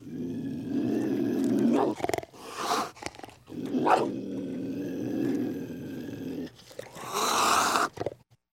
puma-sound